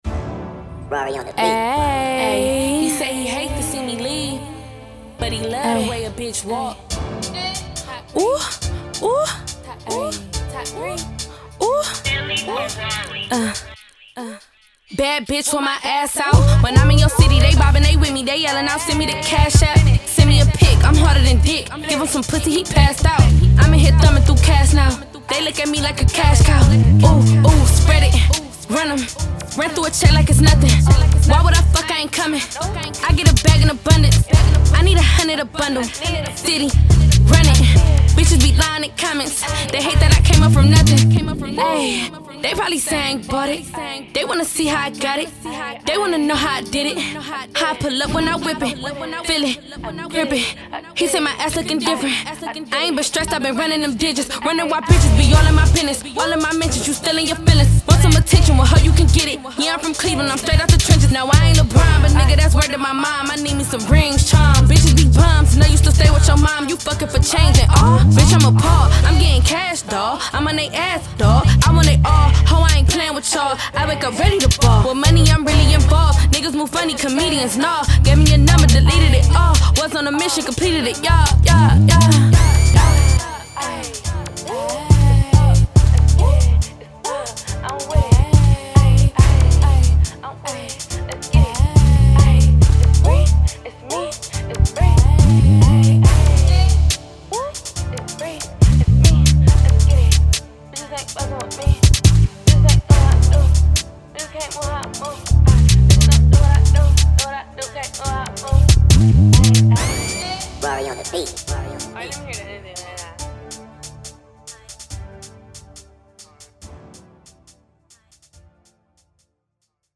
Hiphop
quick freestyle
taps into a Drill Music vibe